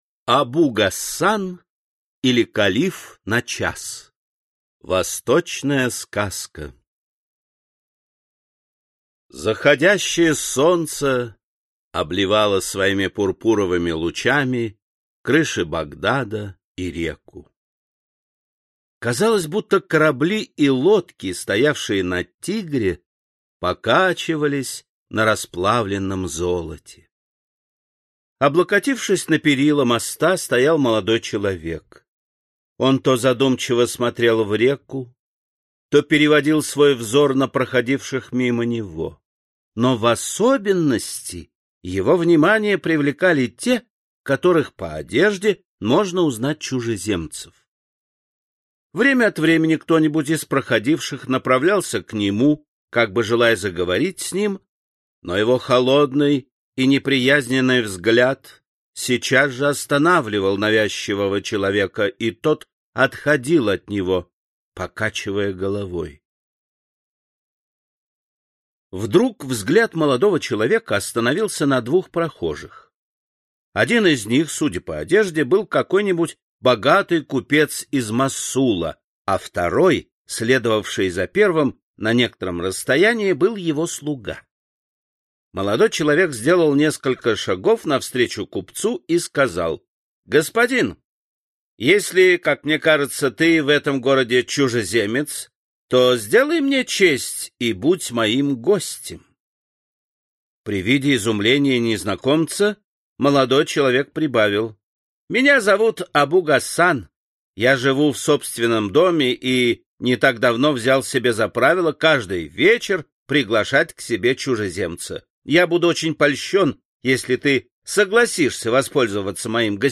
Аудиокнига Золотая книга сказок. Восточные сказки | Библиотека аудиокниг